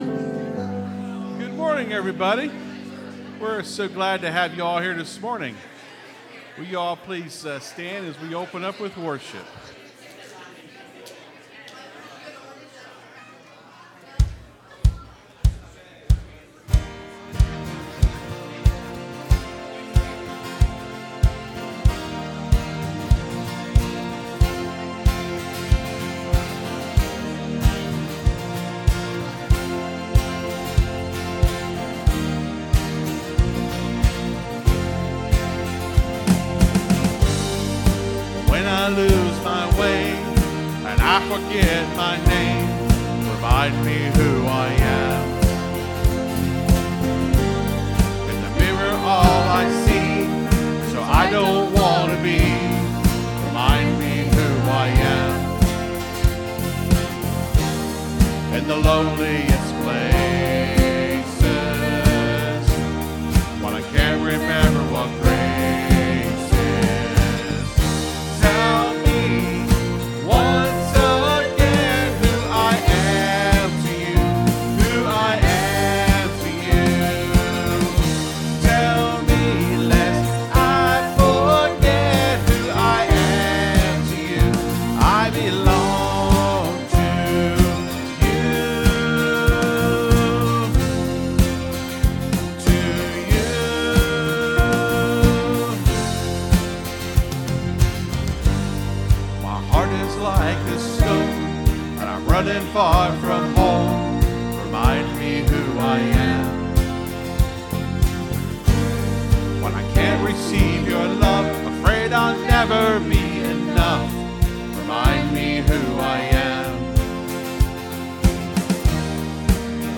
(Sermon starts at 28:10 in the recording).